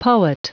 Prononciation du mot poet en anglais (fichier audio)
Prononciation du mot : poet